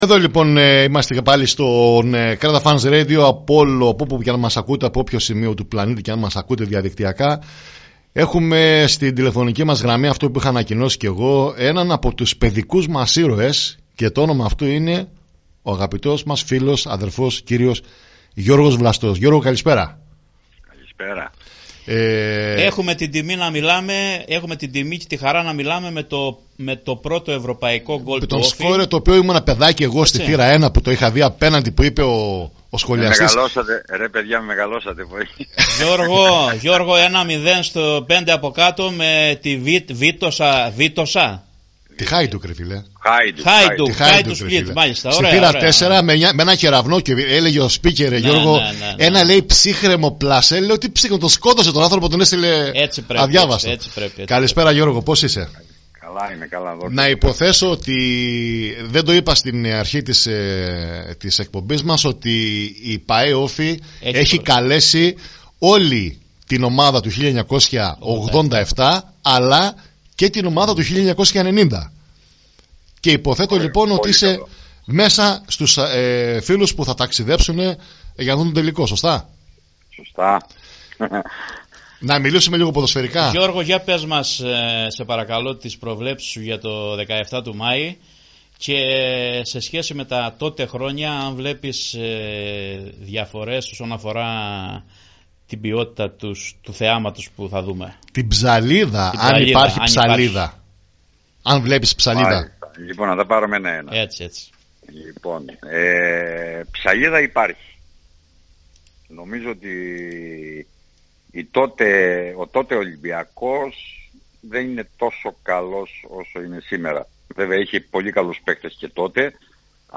Το απόγευμα της Παρασκευής (9/5), η εκπομπή “Ofi Fans” του Creta Fans Radio, φιλοξένησε τον θρυλικό σκόρερ και αρχηγό της ομάδας, Γιώργο Βλαστό, ο οποίος κλήθηκε να δώσει την δική του οπτική για την μεγάλη αναμέτρηση στο ΟΑΚΑ, ανάμεσα σε ΟΦΗ και Ολυμπιακό.